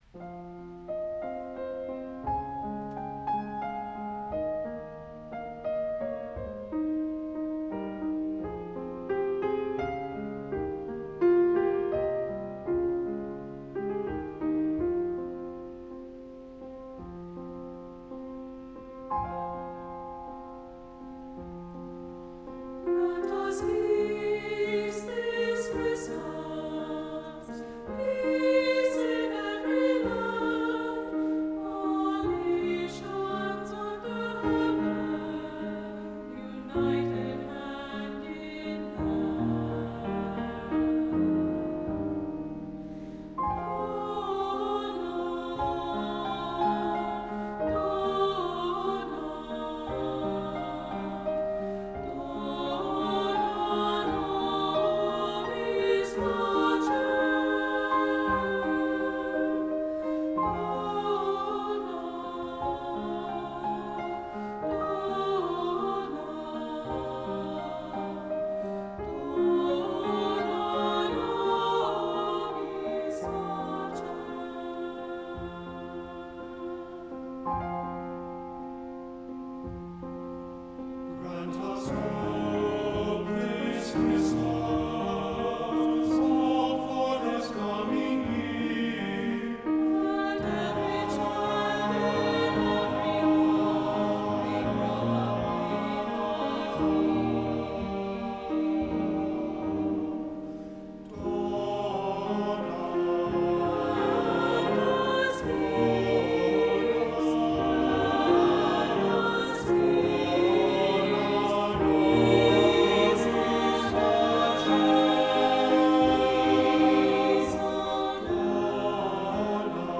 Voicing: "SAB"